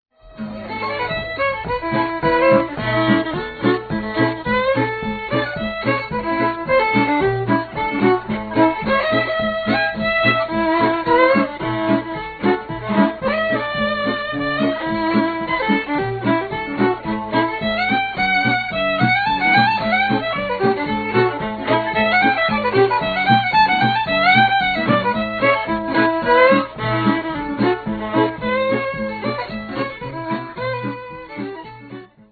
Kentucky fiddler